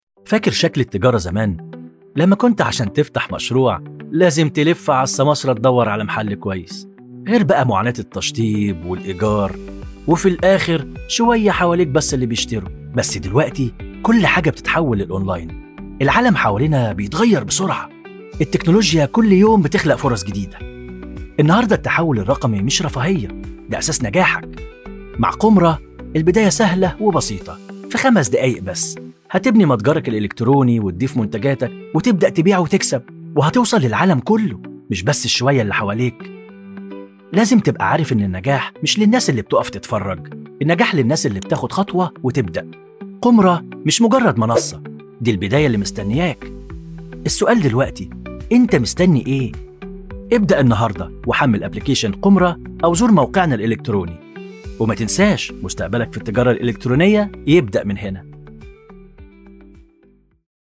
اعلان عن تطبيق متجر الكترونى
• ذكر
• دعاية وإعلان
• العامية المصرية (القاهرة)
• باريتون Baritone (متوسط العرض)
• في منتصف العمر ٣٥-٥٥